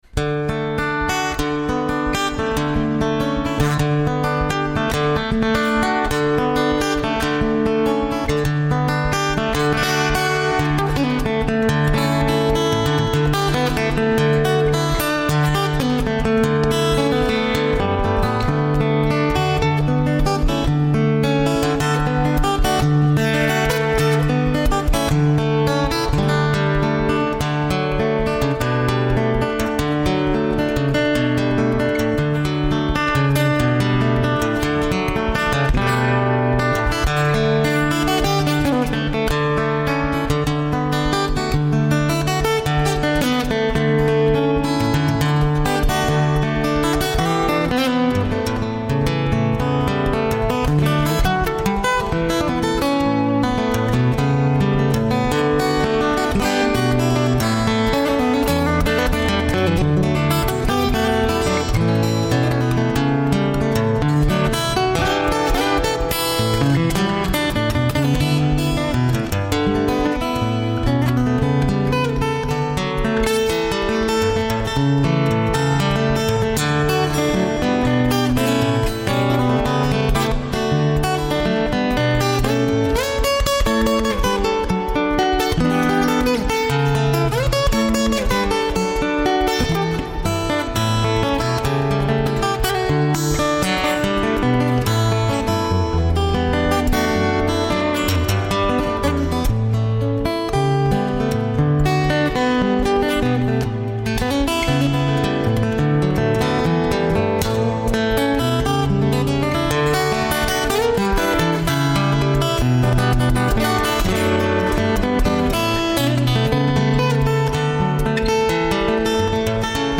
Un evento straordinario con uno dei più grandi interpreti della chitarra acustica a livello mondiale